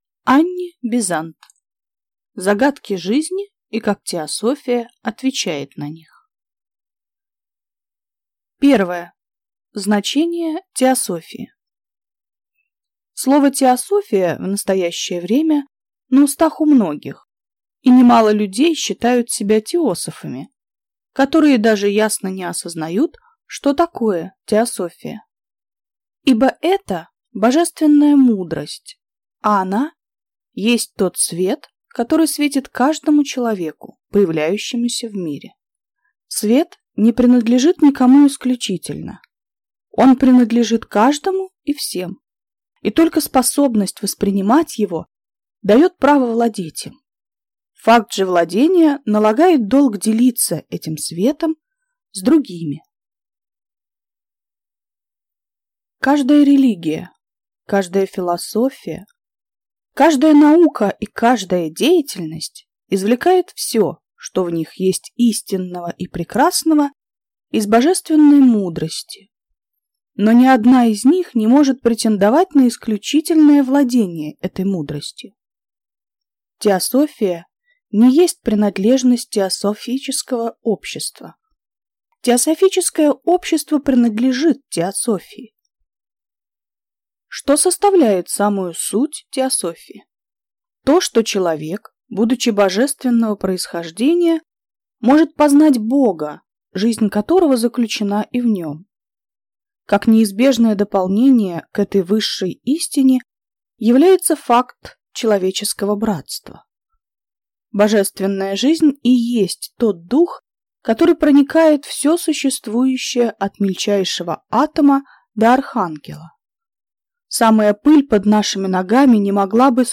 Аудиокнига Загадки жизни и как теософия отвечает на них | Библиотека аудиокниг